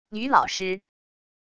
女老师wav音频
女老师wav音频生成系统WAV Audio Player